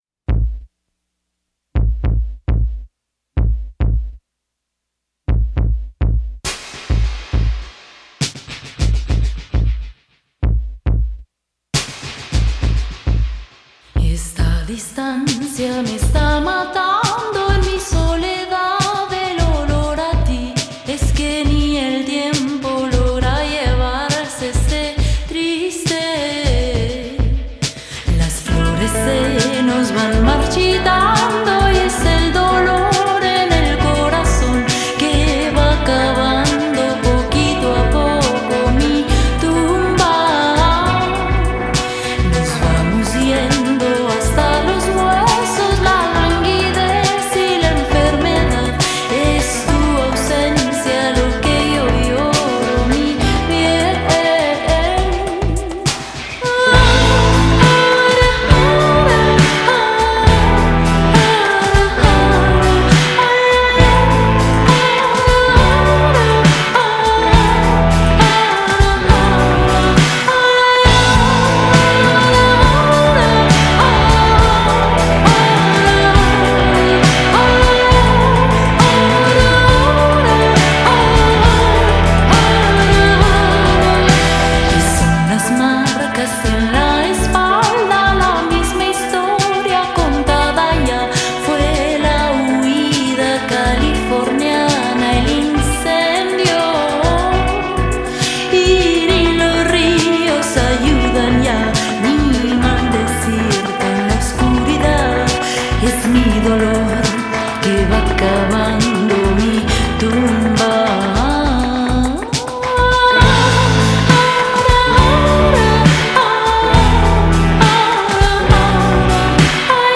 La parte musical, impecable y armoniosa.